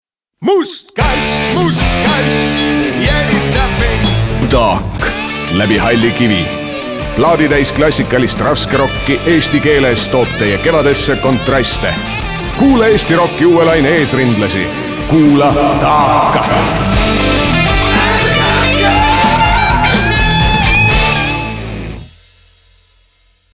Taak_raadioreklaam_LHK.mp3